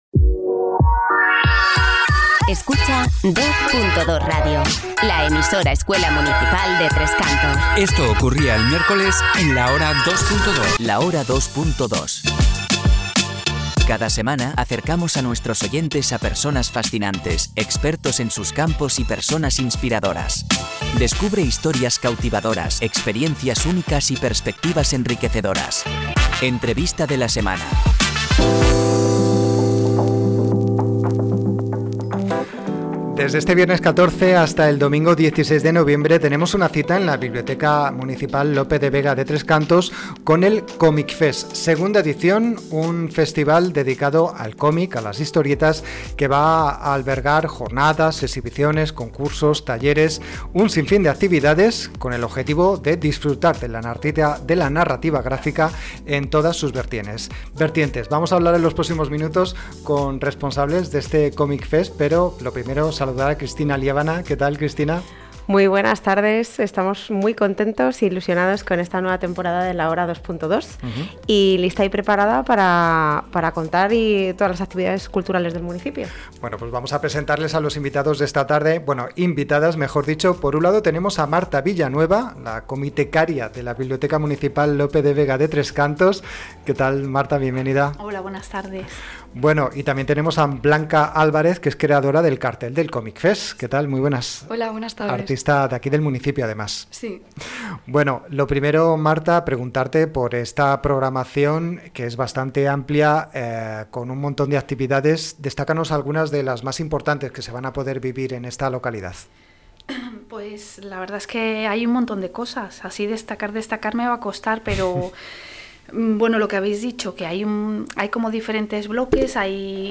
Entrevista-Programa-del-ComicFest-2025.mp3